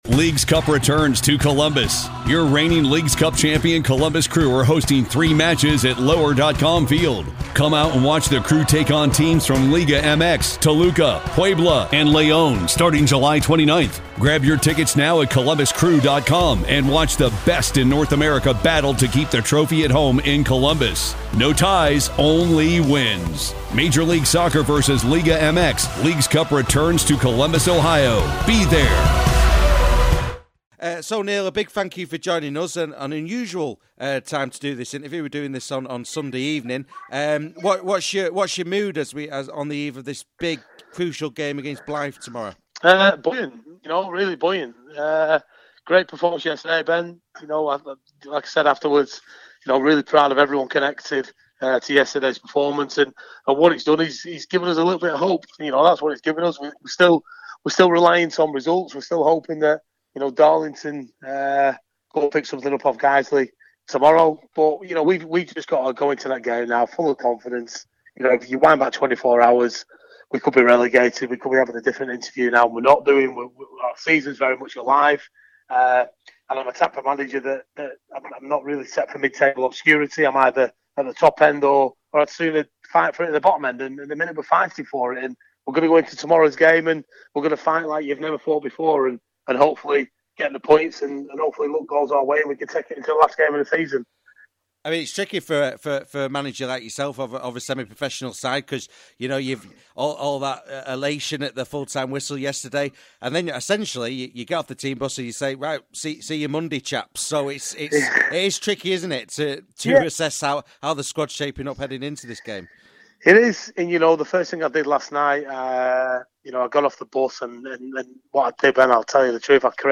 Pre Match Interview